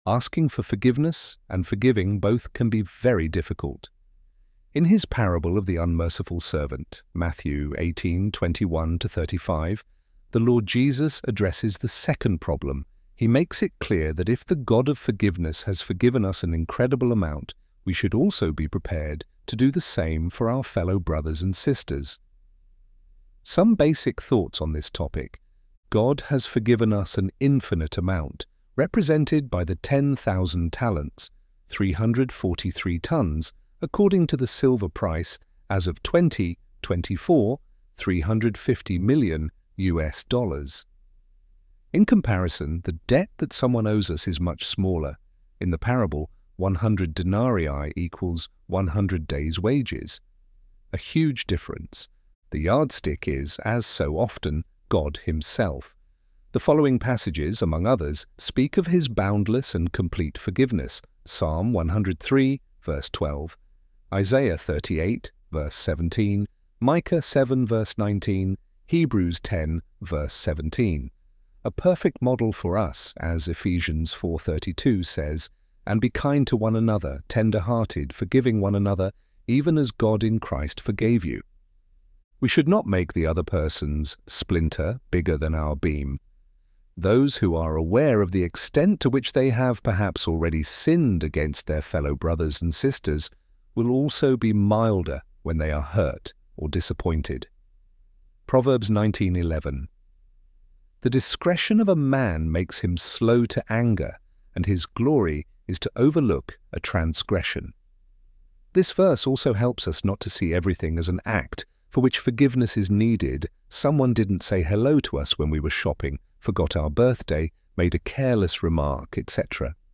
ElevenLabs_Forgiveness_—_Difficult_but_Possible.mp3